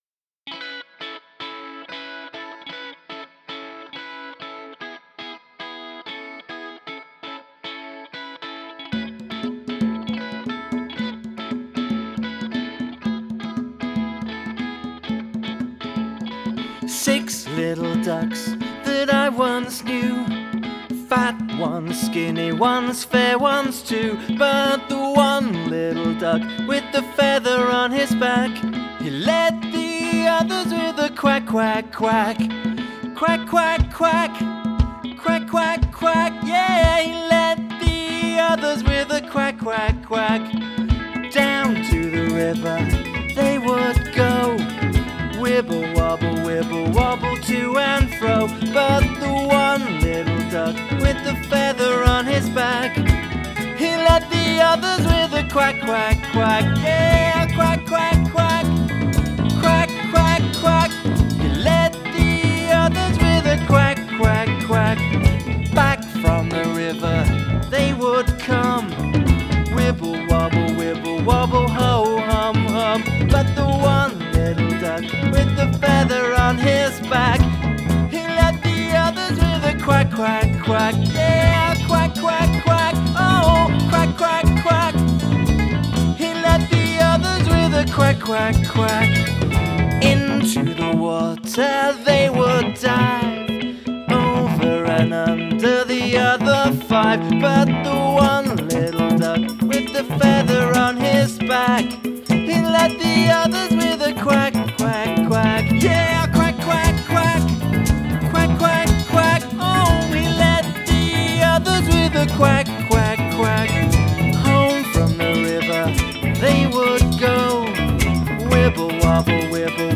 Counting Songs
traditional children's action song